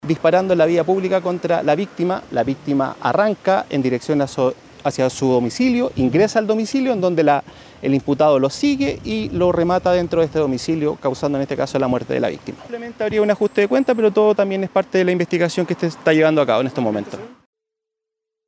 Los hechos fueron confirmados por el fiscal del Equipo Contra el Crimen Organizado y Homicidios, Claudio Astica, quien agregó que es “probable” que se trate de un ajuste de cuentas.